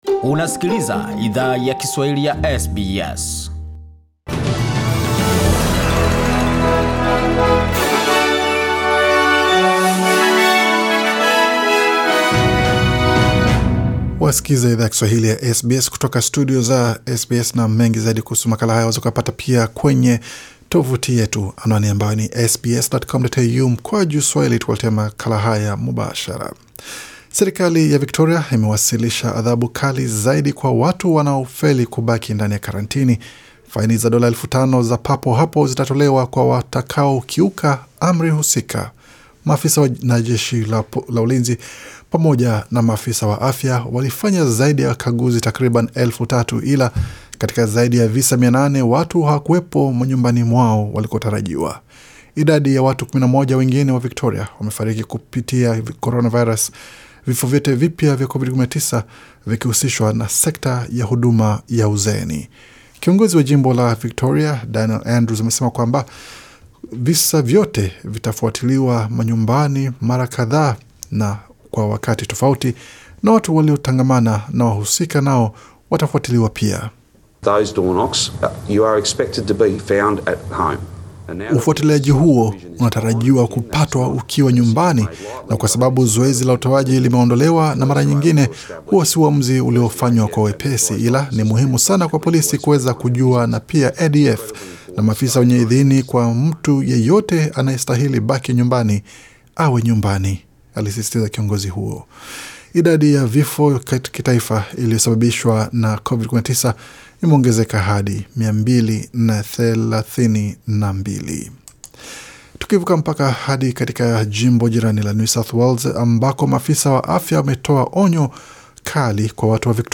Taarifa ya habari 4 Agosti 2020